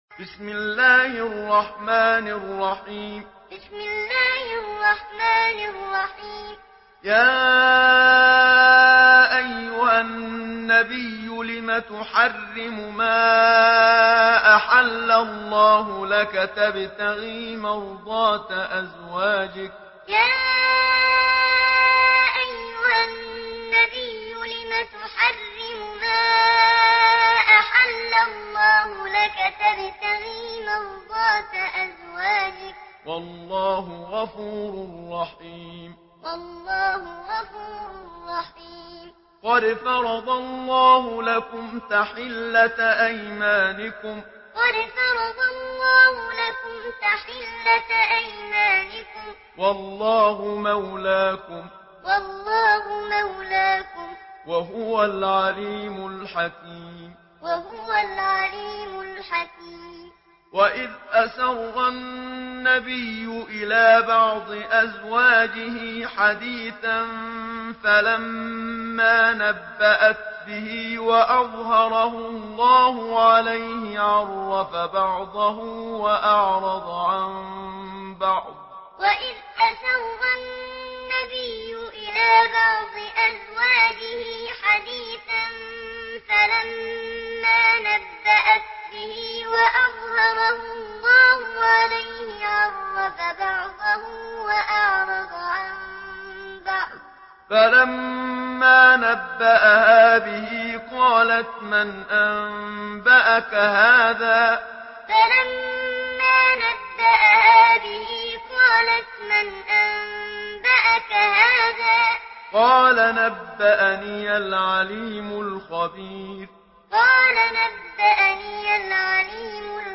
سورة التحريم MP3 بصوت محمد صديق المنشاوي معلم برواية حفص